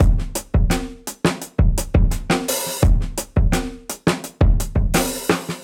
Index of /musicradar/dusty-funk-samples/Beats/85bpm
DF_BeatB_85-03.wav